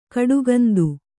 ♪ kaḍugandu